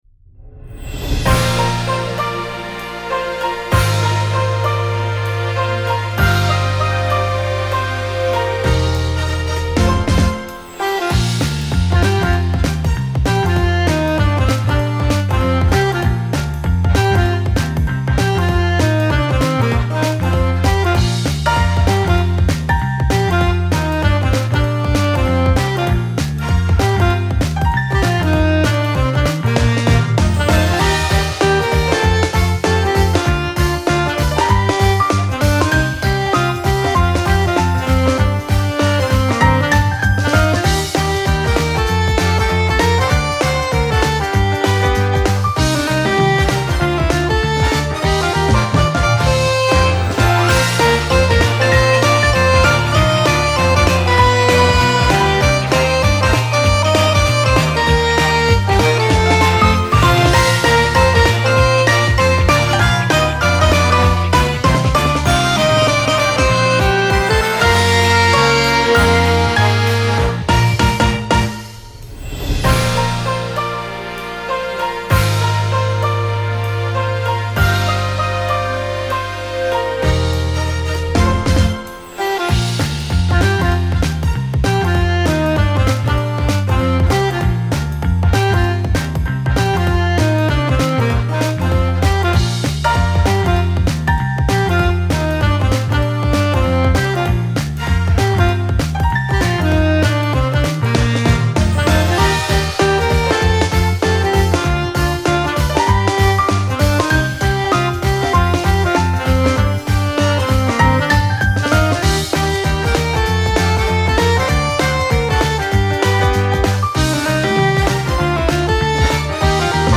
ノリのよいおしゃれで明るいジャジーPOPな曲です。
※曲の３週目はメロディを抜いてあります。